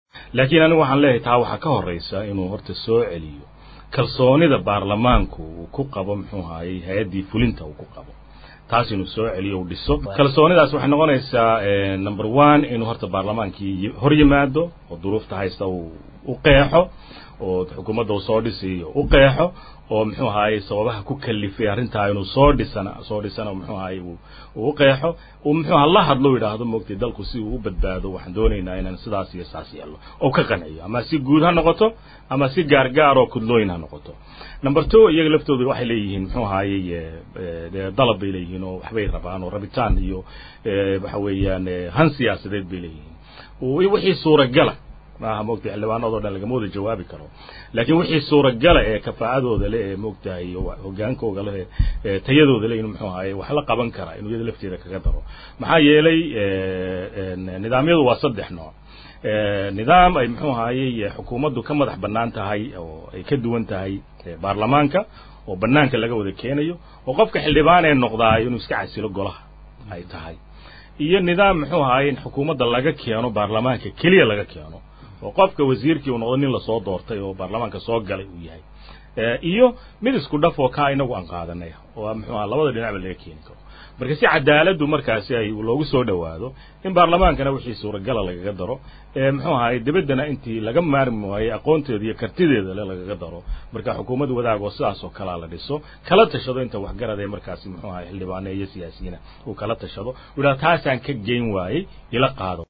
Hadalkaan ayuu Xildhibaan Cilmi Maxamuud Nuur ka sheegay ka dib markii uu ka qeyb galay Barnaamijka kulanka Todobaadka ee ka baxa Idaacada Dowlada.